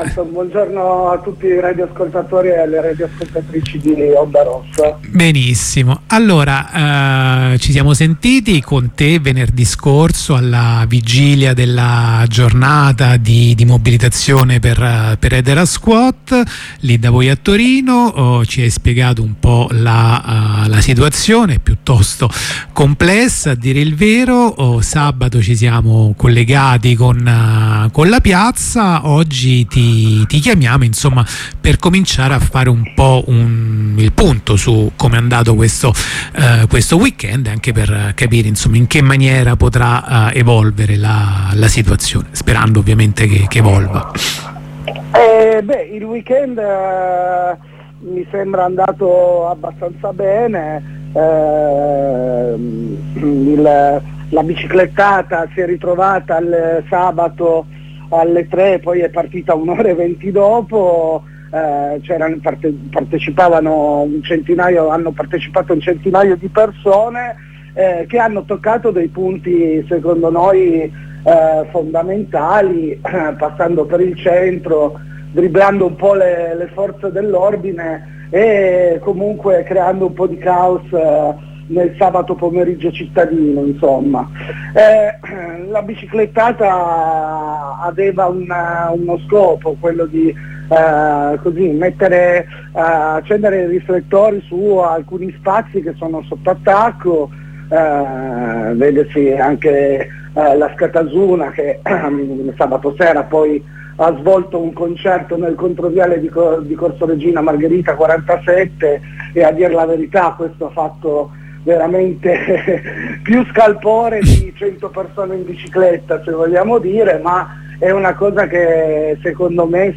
dopo un prolungato periodo di assenza, ritorniamo a "infestare" l'etere nella notte di ondarossa. sentiamo un compagno di strike spa, che ci illustra il "marci su roma vol. iv".